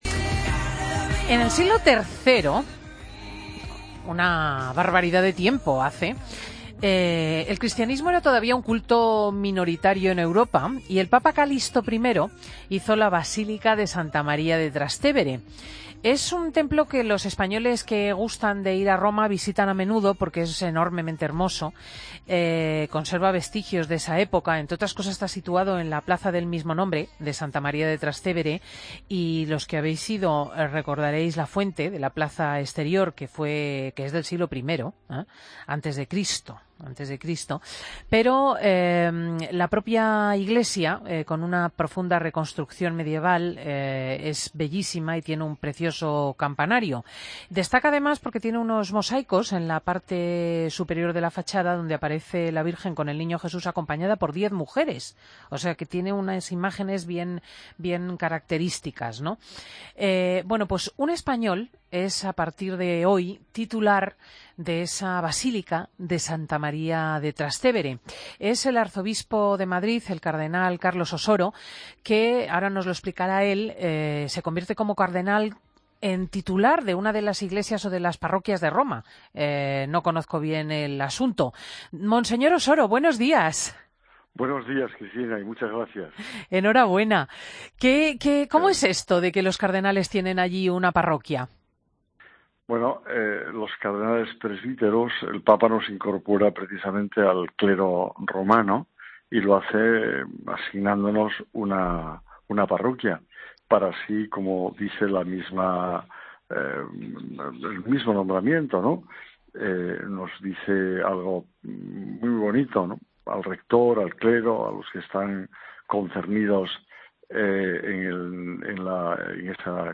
Escucha la entrevista al cardenal Carlos Osoro en Fin de Semana